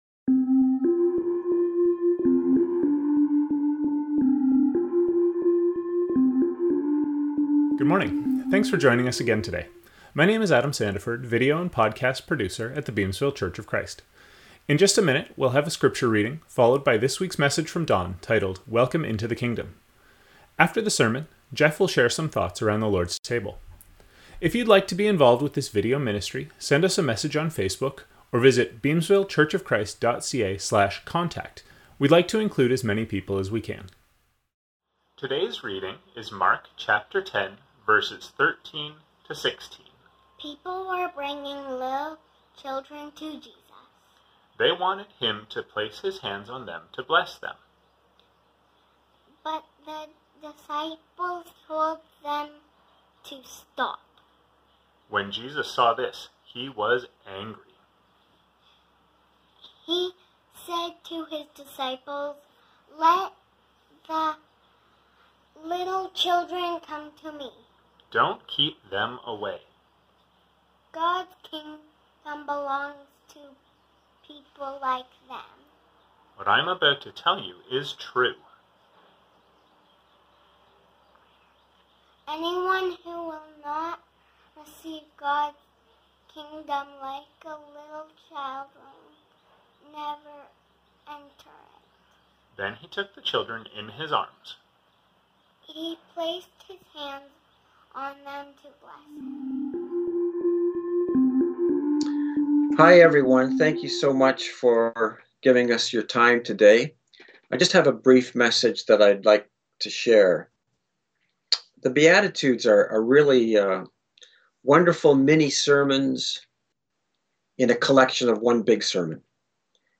Sermon scriptures: Matthew 5:3; 5:4; 5:5; 5:6; Mark 10:16; Matthew 6:9; John 17:20-21; 17:23; 1 Corinthians 1:2; John 3:16; 2 Corinthians 5:19; 1 John 2:2; Colossians 1:19-20; Ephesians 1:10;